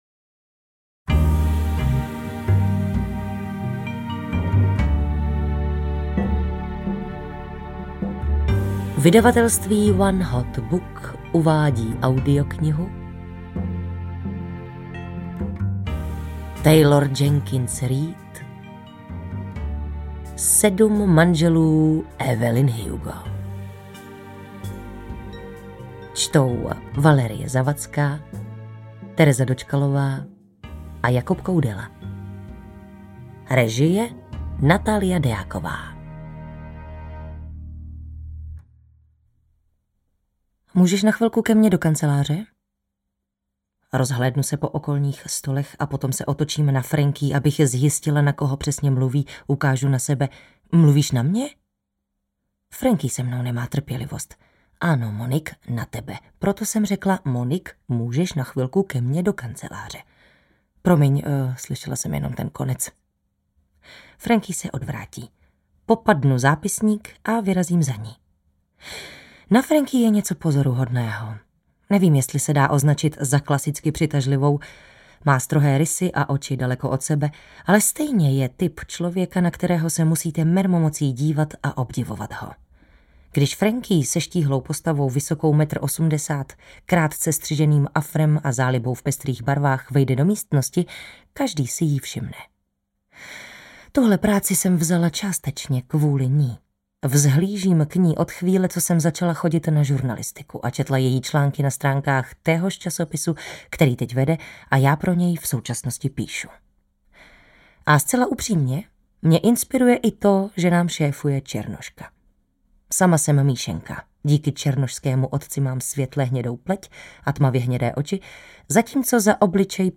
Ukázka z knihy
sedm-manzelu-evelyn-hugo-audiokniha